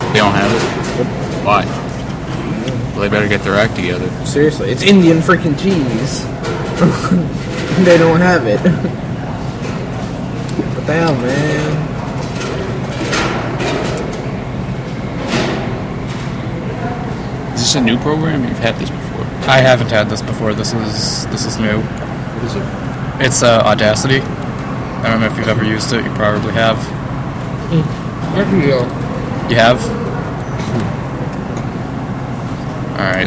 Field Recording 2
Student Center cafe.
Sounds heard: Voices in foreground discussing. Metal clanking, possibly something happening in CPK or Starbucks opening up.